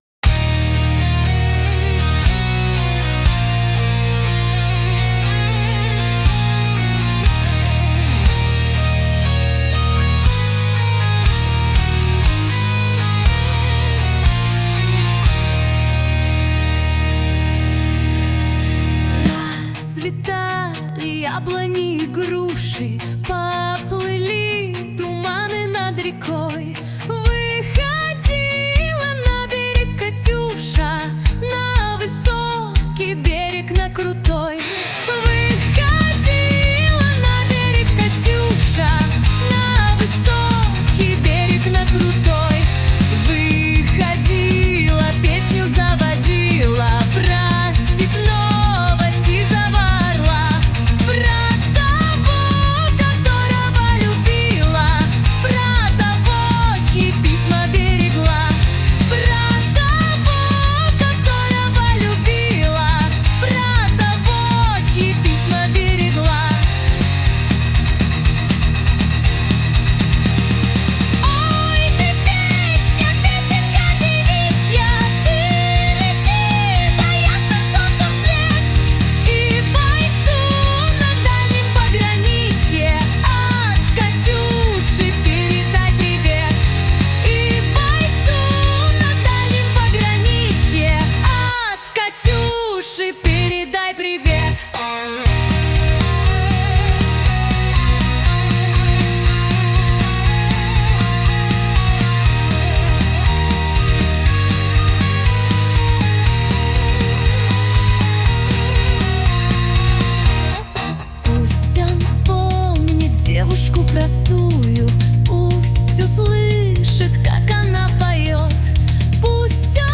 В Стиле Hard Rock Cover